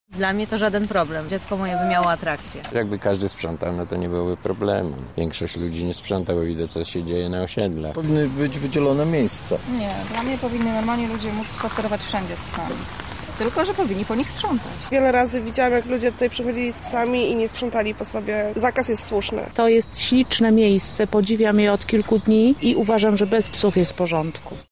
Zapytaliśmy mieszkańców, co sądzą na temat obecności psów w Ogrodzie Saskim: